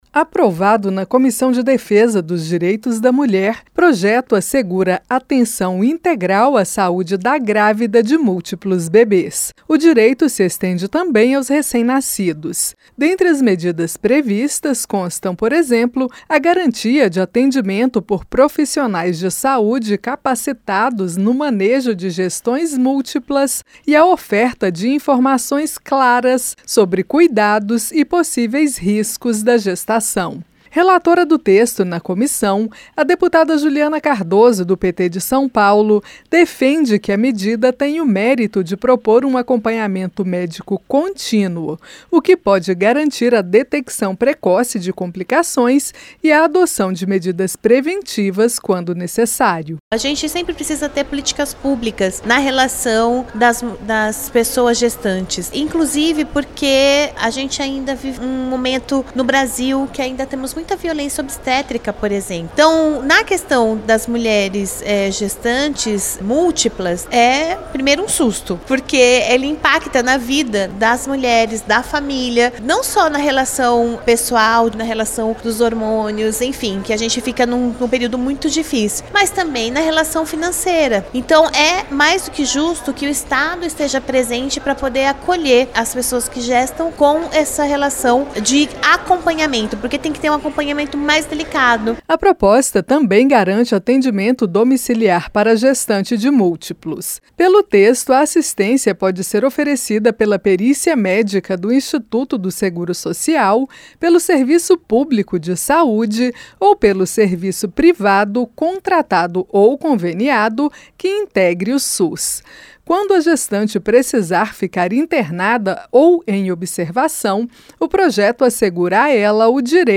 COMISSÃO APROVA PROJETO QUE GARANTE ASSISTÊNCIA INTEGRAL À GRÁVIDA DE MÚLTIPLOS. A REPÓRTER